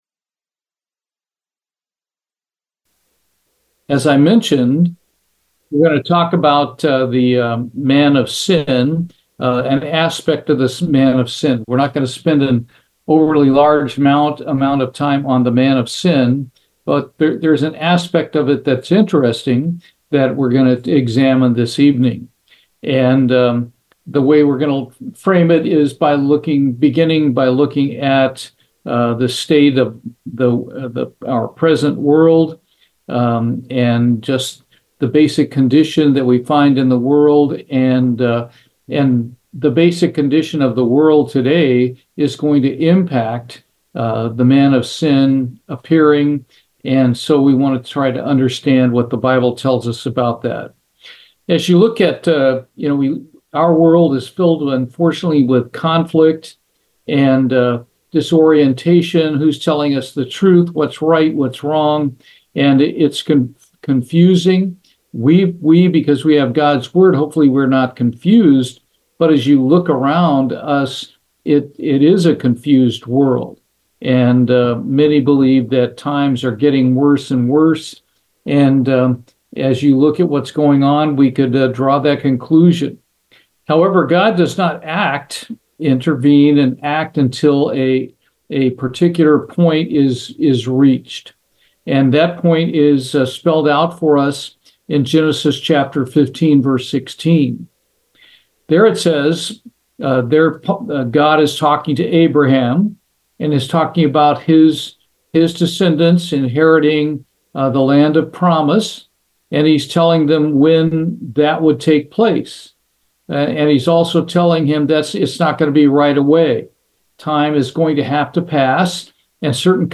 Bible Study, What Restrains the Man of Sin